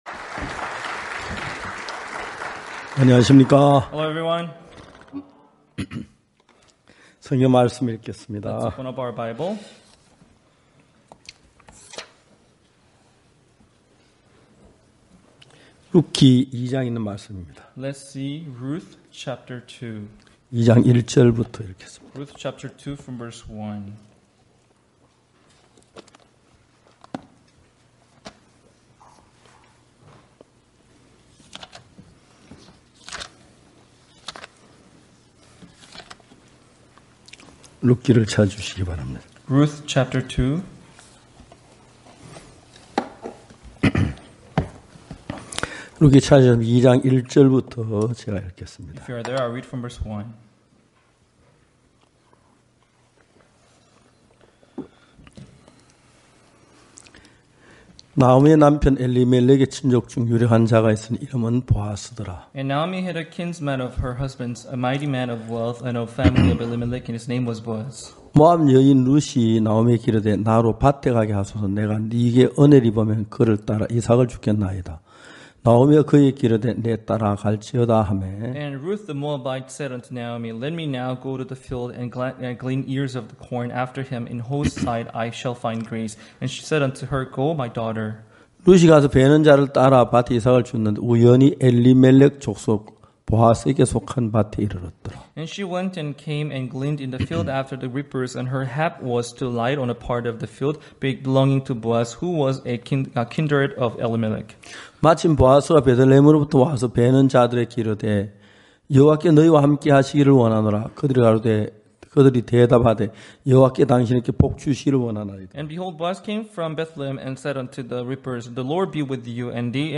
전국 각 지역의 성도들이 모여 함께 말씀을 듣고 교제를 나누는 연합예배.
연합예배 마리아와 마르다를 믿음으로 이끄시는 예수님